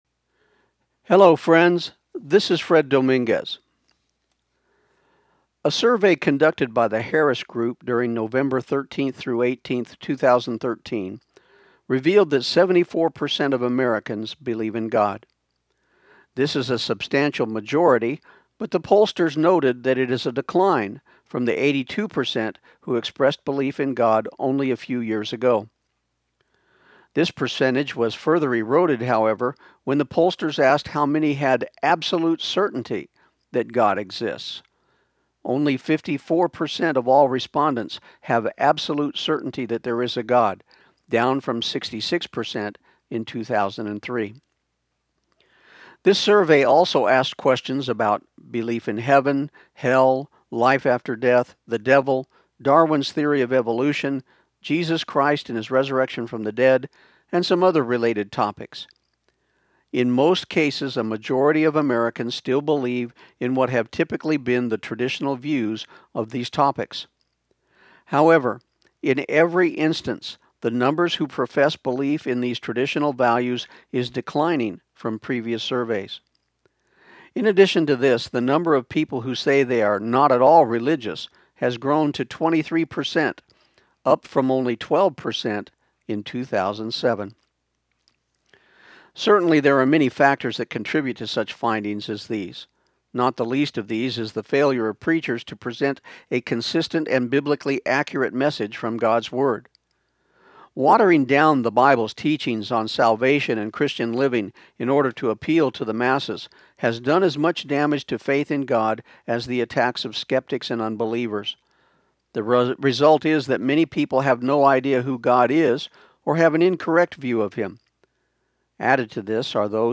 This program aired on KIUN 1400 AM in Pecos, TX on July 9, 2014.